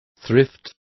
Complete with pronunciation of the translation of thrift.